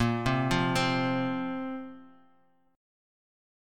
A#sus2 chord